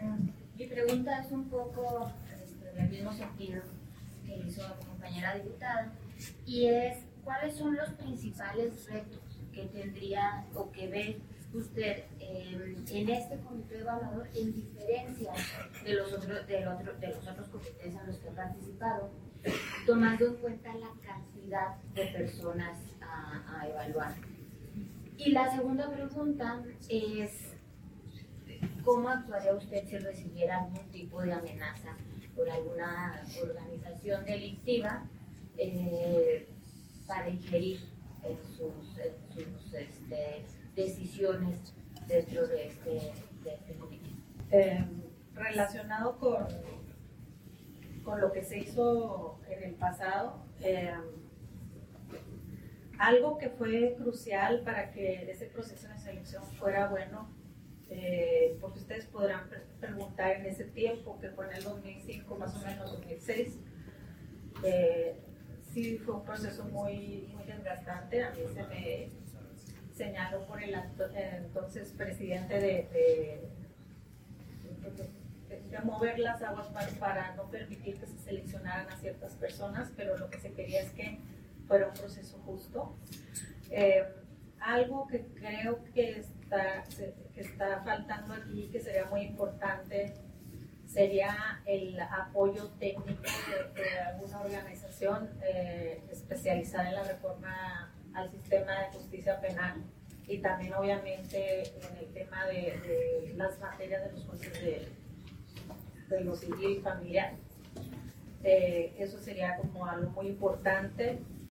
Entrevista Jucopo a candidatos para el Comité de Evaluación de elección judicial
AUDIO-JUCOPO-ENTREVISTAS-ASPIRANTES.mp3